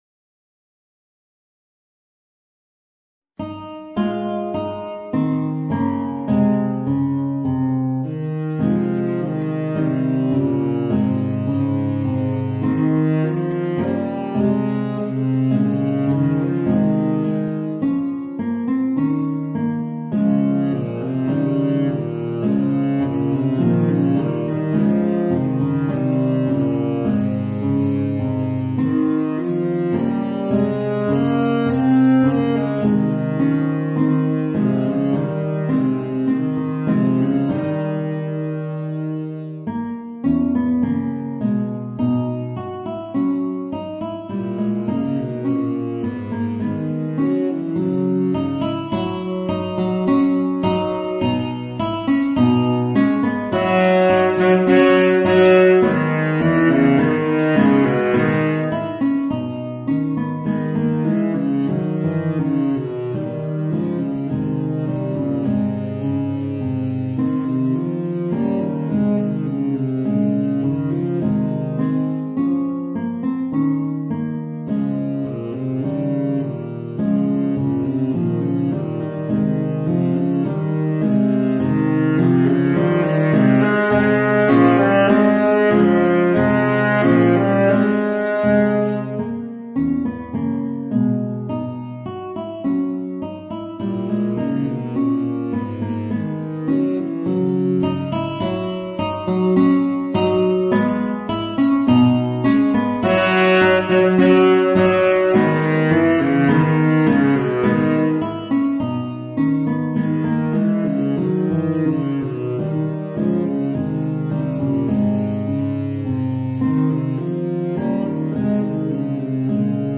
Voicing: Guitar and Baritone Saxophone